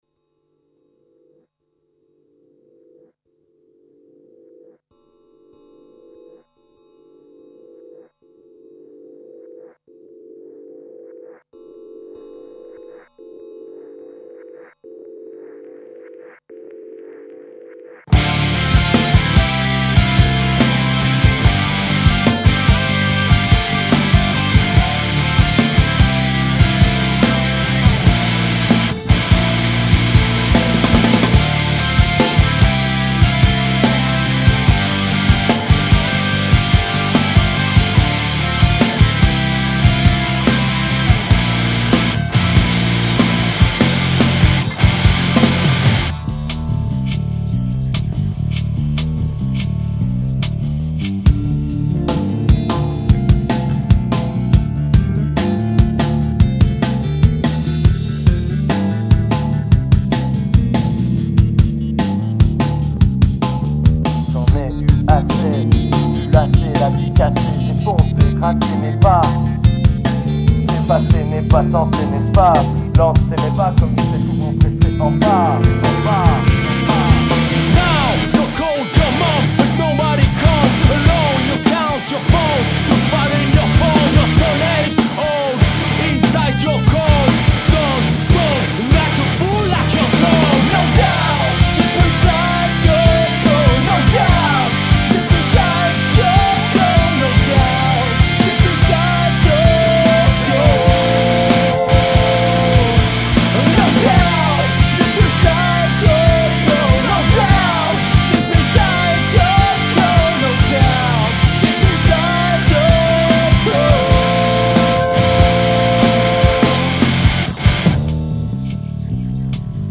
Estilo: Metal